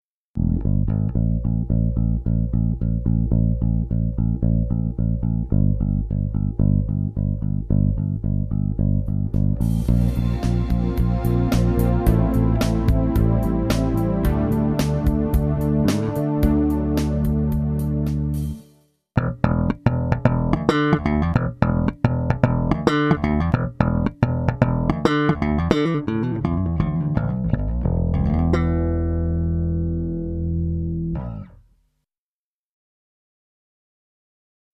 e-bass.mp3